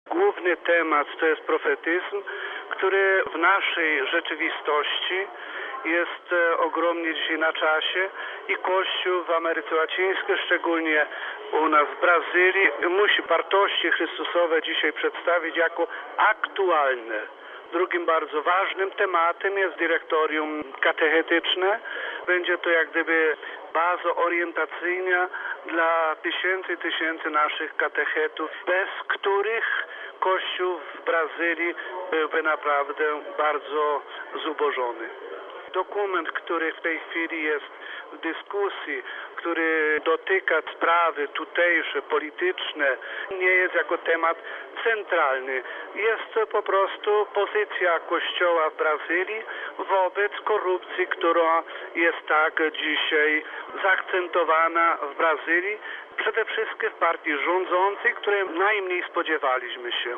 Home Archivio 2005-08-12 16:04:42 Bp Stanula o obradach episkopatu Brazylii W Itaici niedaleko São Paulo obraduje 43 sesja plenarna episkopatu Brazylii. O poruszanych na niej tematach mówi ordynariusz diecezji Itabuna, biskup Czesław Stanula: All the contents on this site are copyrighted ©.